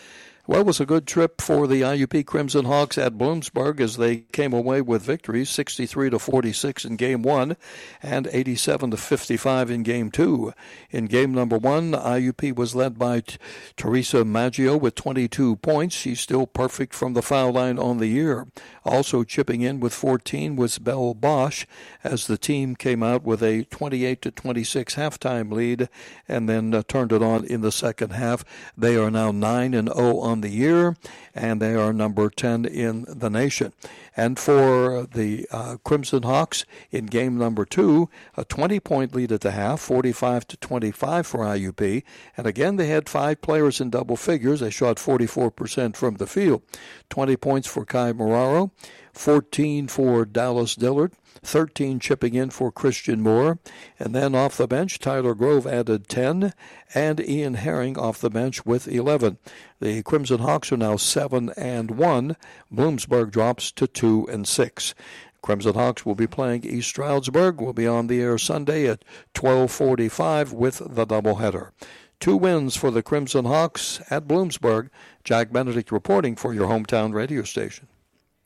had the call on 92.5 FM, U92.